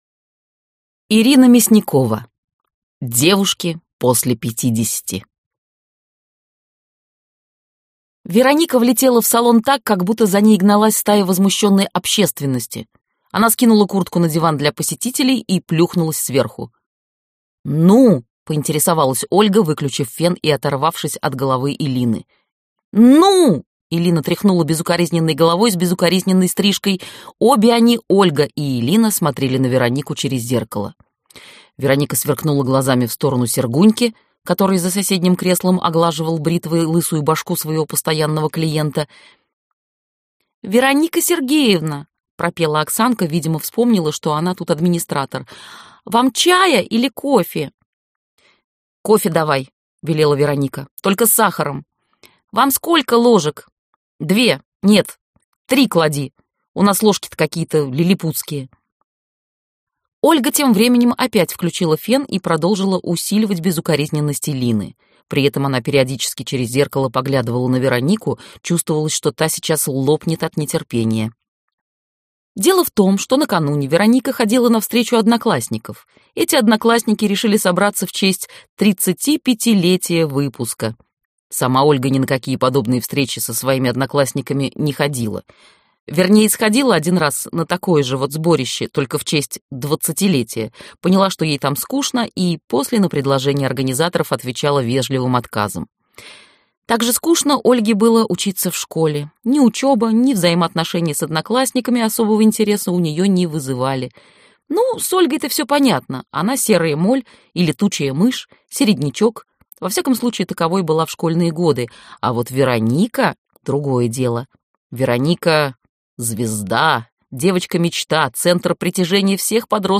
Аудиокнига Девушки после пятидесяти | Библиотека аудиокниг